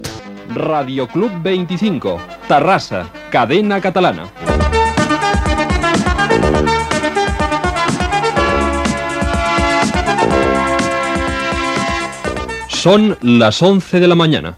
Indicatiu de l'emissora formant part de Cadena Catalana i hora.
FM